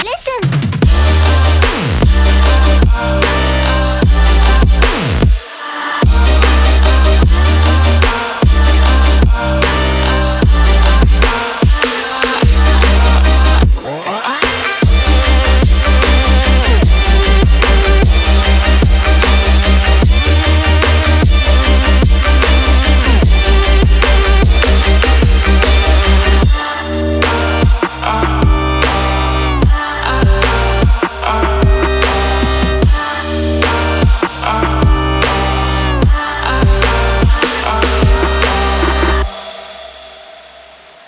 • Качество: 320, Stereo
громкие
Electronic
Trap
качает
Стиль: future bass